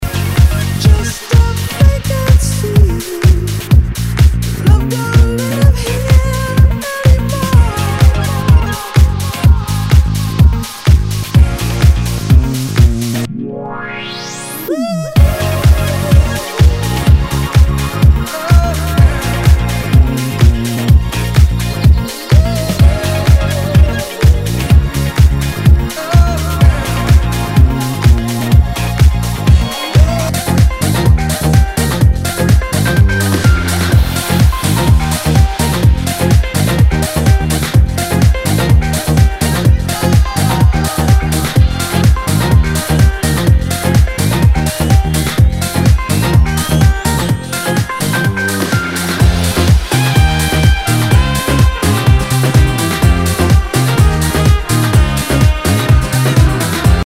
HOUSE/TECHNO/ELECTRO
ナイス！ファンキー・ハウス！！